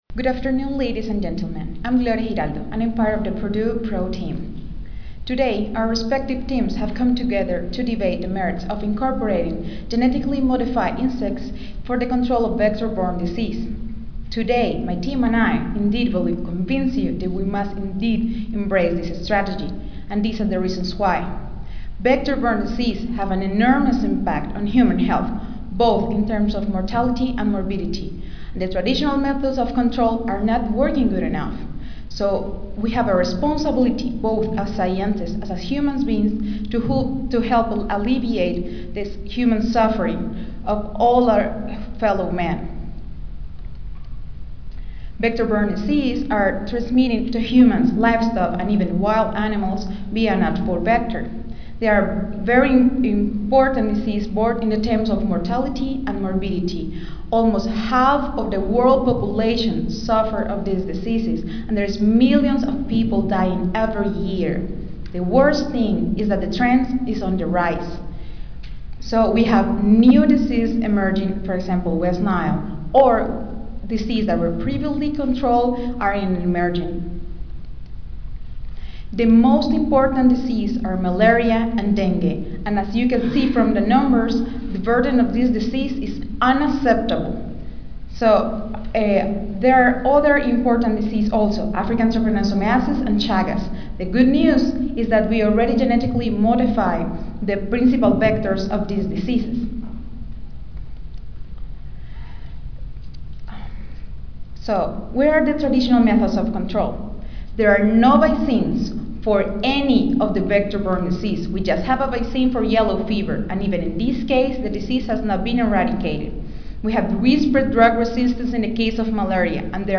Student Debate: The Impacts of Genetically Modified Organisms in Entomology
Room A1, First Floor (Reno-Sparks Convention Center)